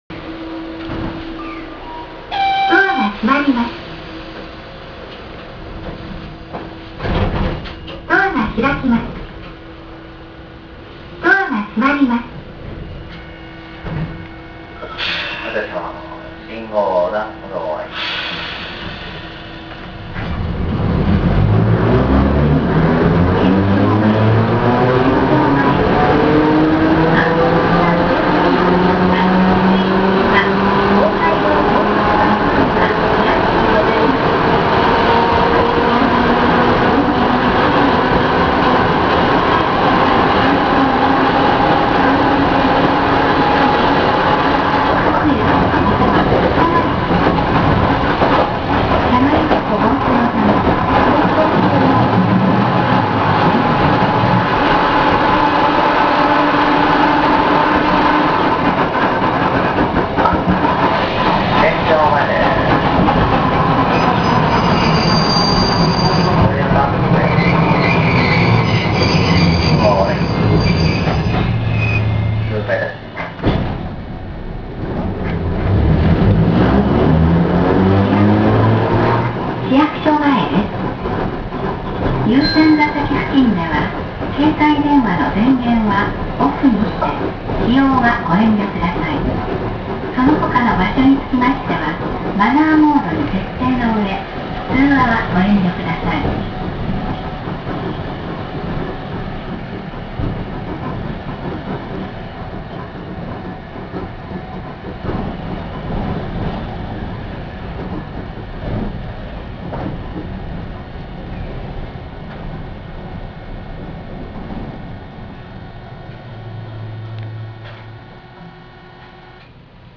・50形走行音
【城南線】大街道→県庁前（2分7秒：690KB）…68号にて
一応前・中・後期で分けたのですが基本的に音は同じで、全て吊り掛け式。個人的には、数ある路面電車の中でもかなり派手な音を出す部類に感じました。